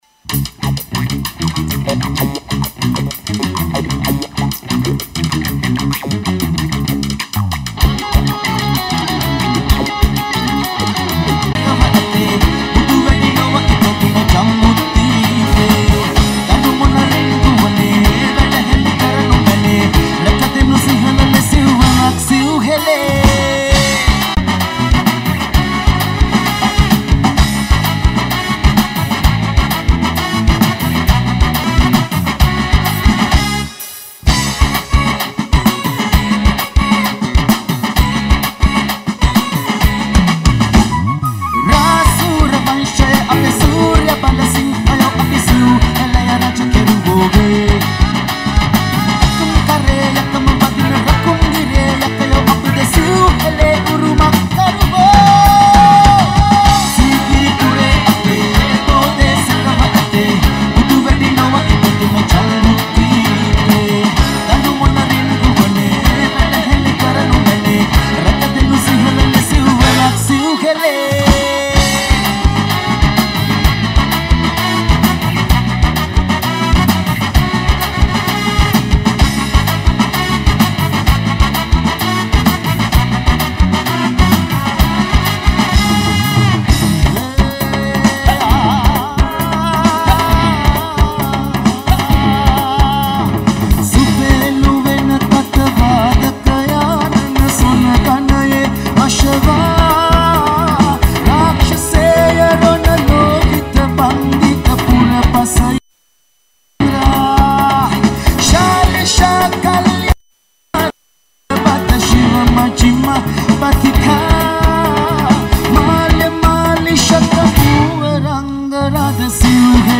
Srilanka No.1 Live Show Download Site...